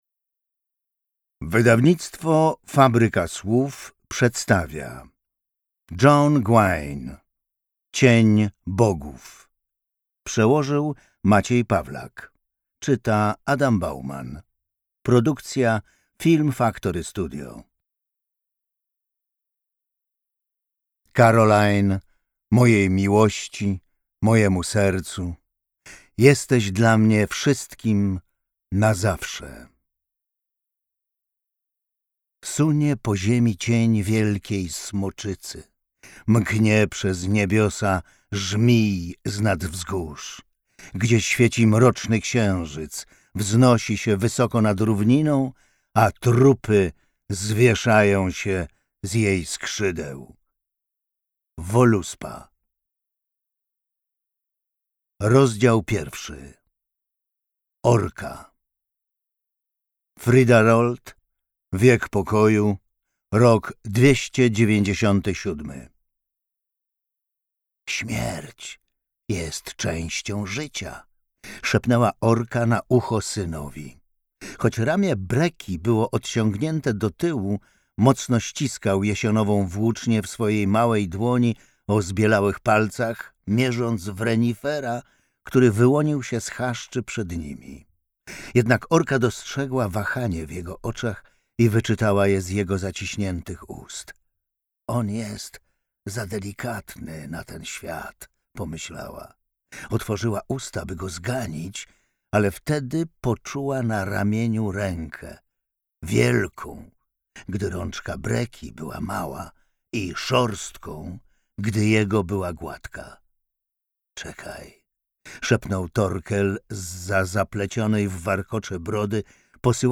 Cień bogów - John Gwynne - audiobook